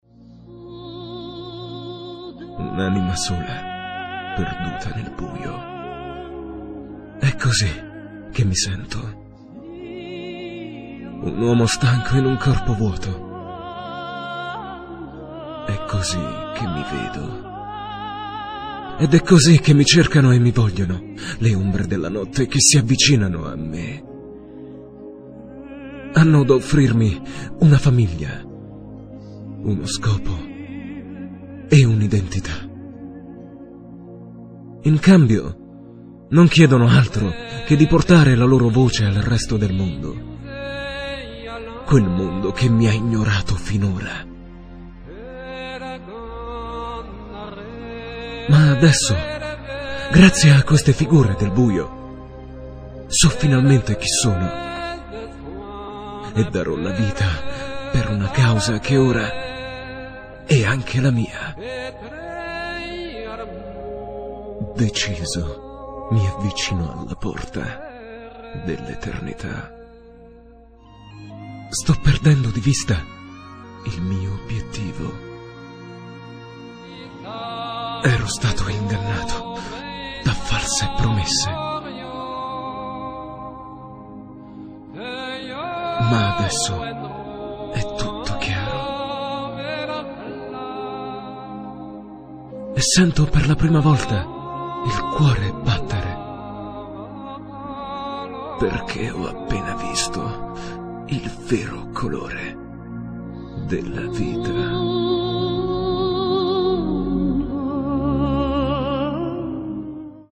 Sprechprobe: Sonstiges (Muttersprache):
Warm italian voice for documntary, e -learning, audioguide, audiobook etc.